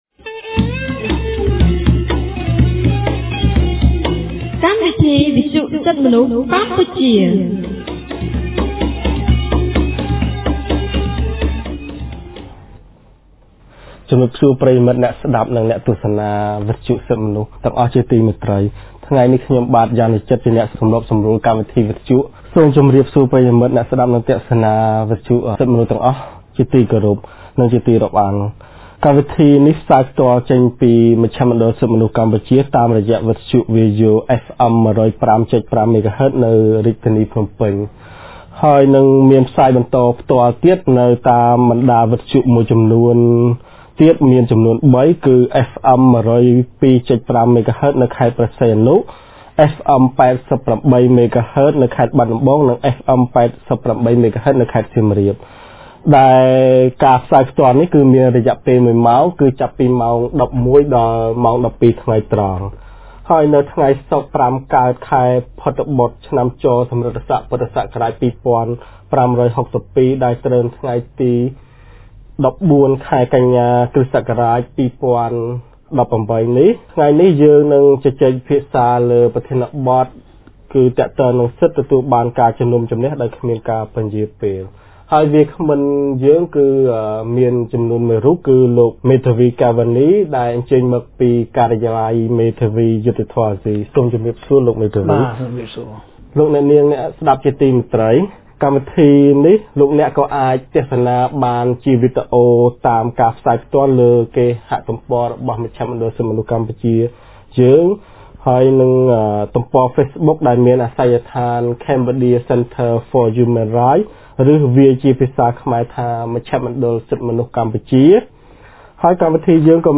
On 14 September 2018, CCHR’s Fair Trial Rights Project (FTRP) held a radio program with a topic on Right to be Tried without Undue Delay.